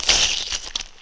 cb_ht_zombi1.wav